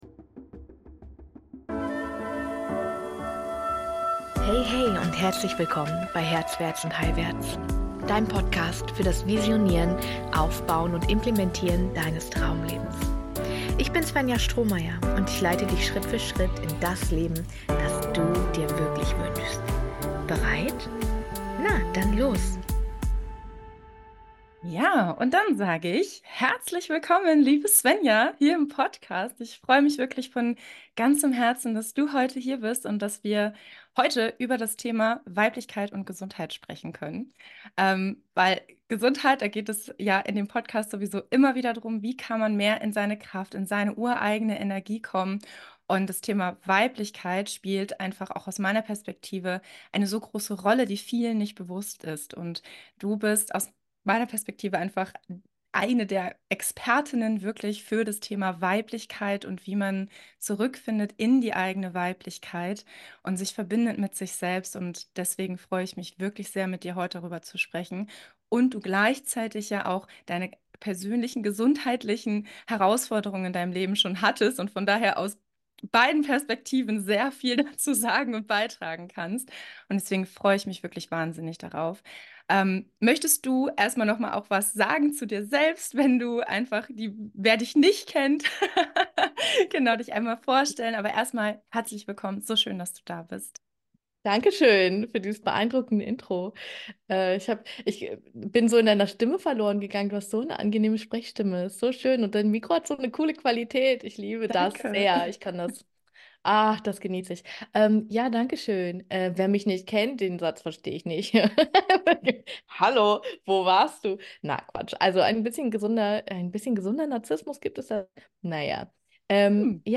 Staffel 2, Folge 071 – Der feminine Weg in die Gesundheit - im Interview